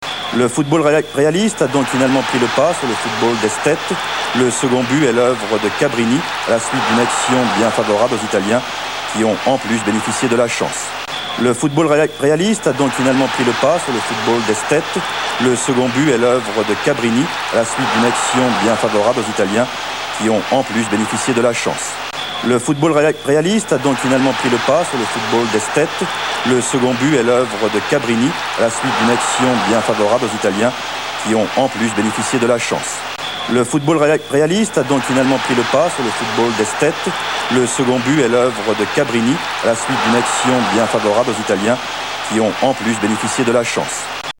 [ Commentaires d'époque ]
diotfootballrealiste.mp3